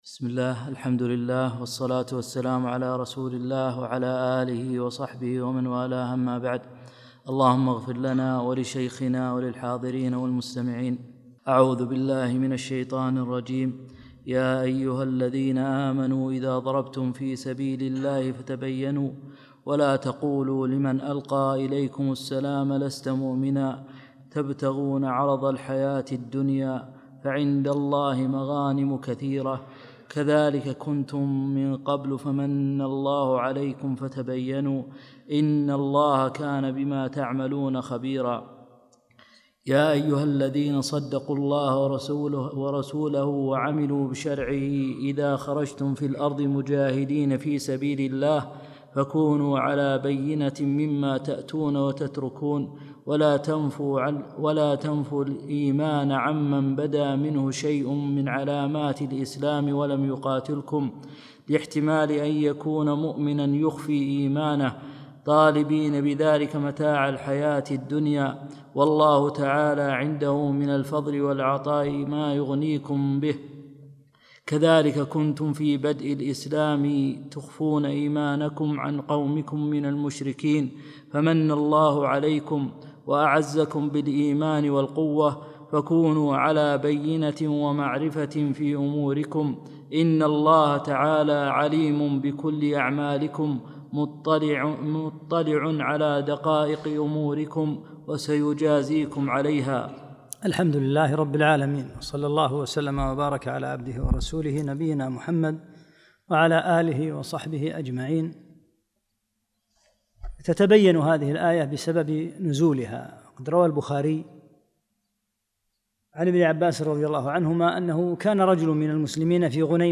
12 - الدرس الثاني عشر